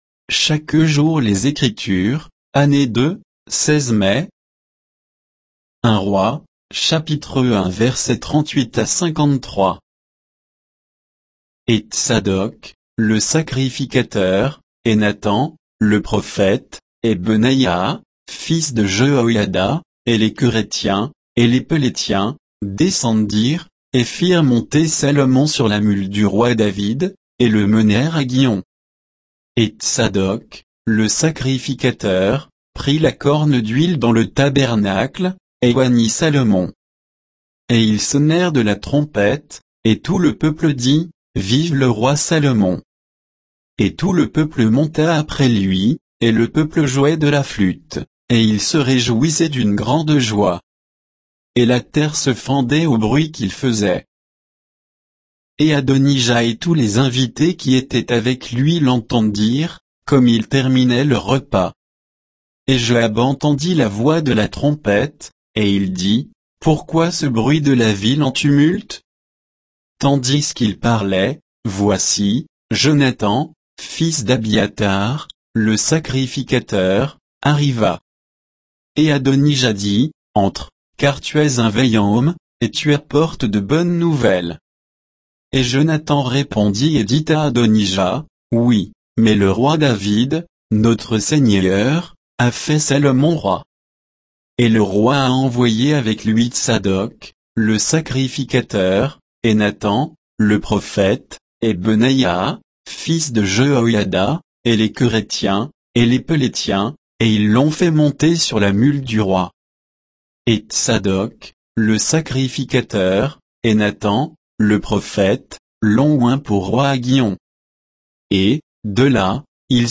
Méditation quoditienne de Chaque jour les Écritures sur 1 Rois 1, 38 à 53